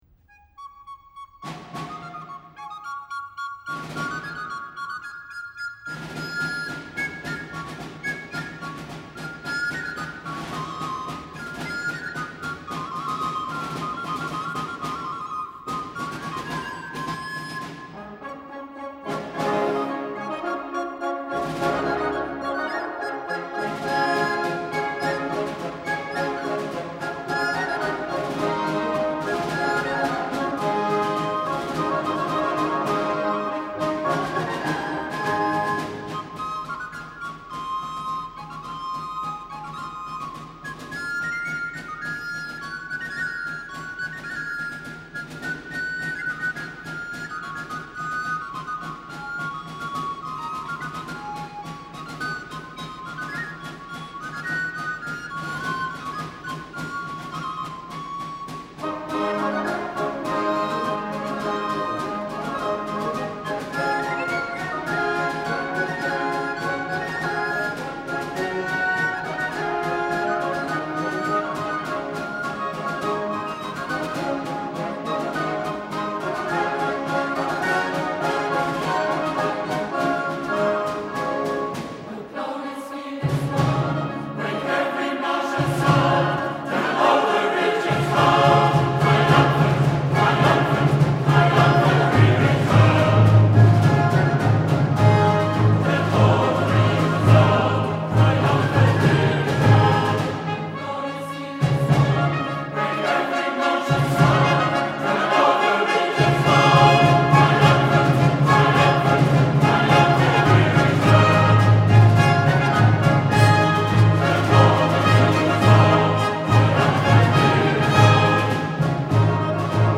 Handel Oratorio
Cet unique album –très belle interprétation et excellente prise de son– : « Israël In Babylon », de George-Frideric Handel,  m’a tout d’abord fait tiquer : d’une part, je ne connaissais pas cet oratorio de Handel, et n’en avais même jamais entendu parler ; d’autre part, la date mentionnée sur la pochette de l’album –1764– est postérieure à la date du décès du compositeur –1759-.